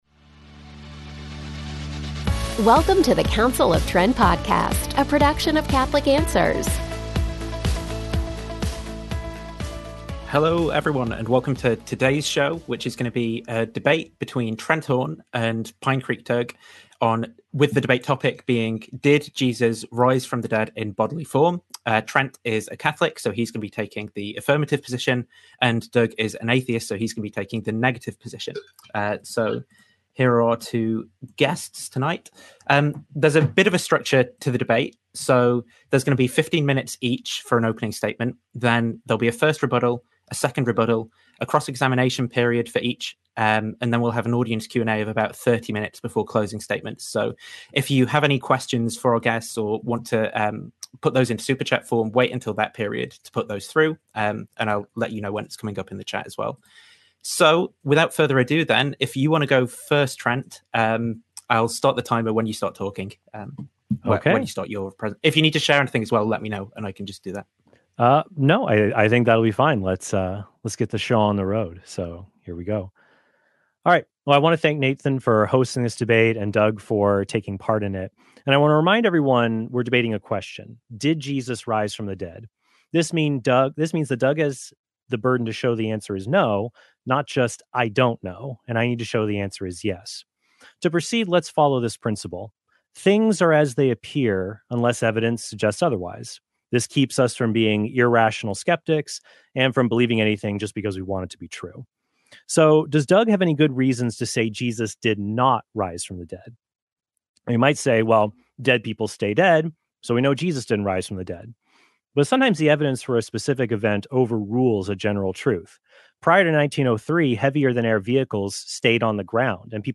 DEBATE: Did Jesus Rise Bodily From the Dead?
There’s a bit of a structure to the debate.